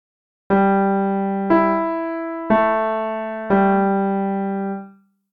Now we’ll use the full major pentatonic scale, adding the so and la notes, and the upper do’ too.
(key: G Major)